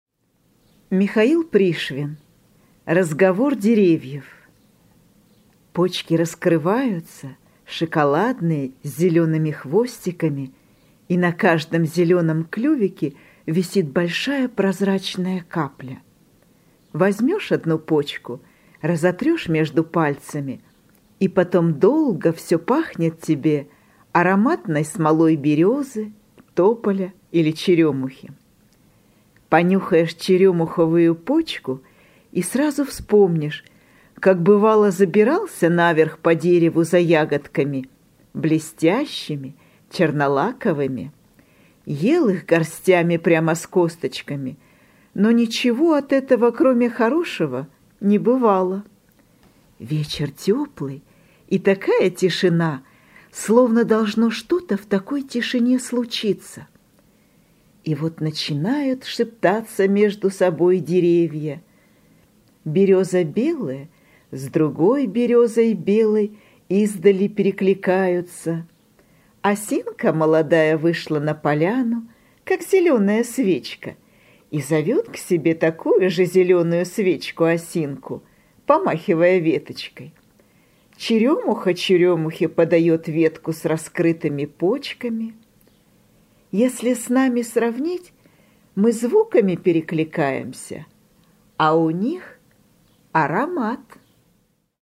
Разговор деревьев - аудио рассказ Пришвина - слушать онлайн | Мишкины книжки
Разговор деревьев – Пришвин М.М. (аудиоверсия)